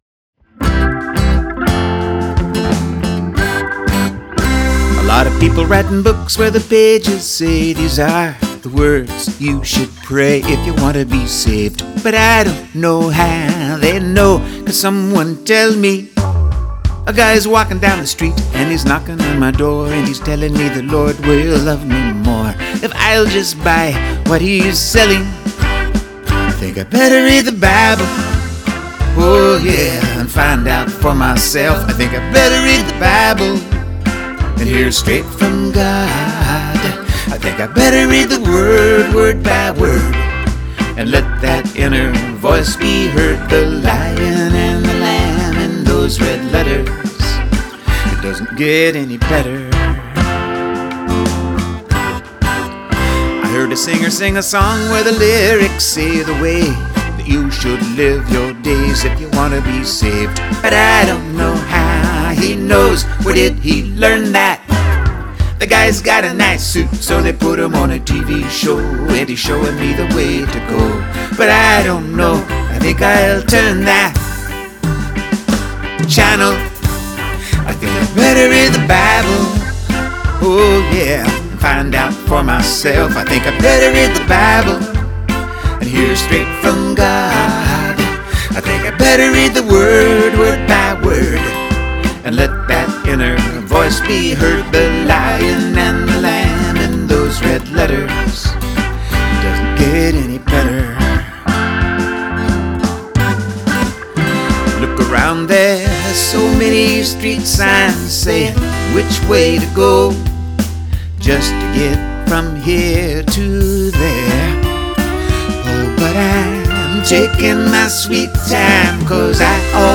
It came out sounding like a polka.
Weird, but sorta fun.
I hit a piece of wood with a little wooden stick.